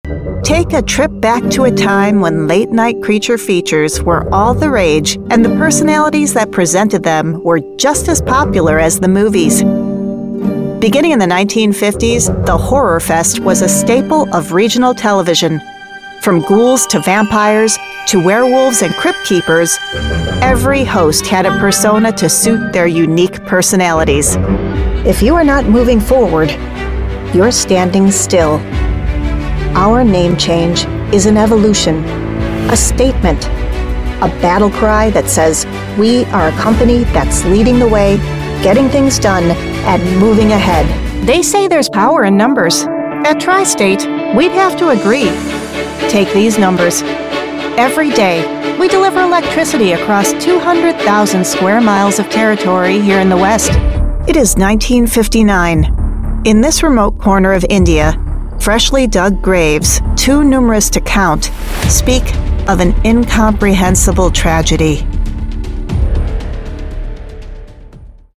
E-learning Voice Over Narration Services | E-book voices
Never any Artificial Voices used, unlike other sites.
Adult (30-50) | Yng Adult (18-29)